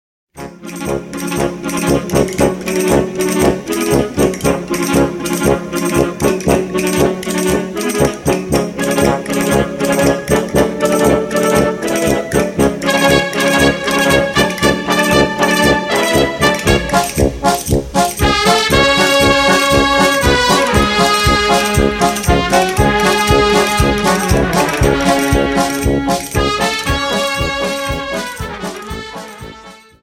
Dance: Paso Doble 59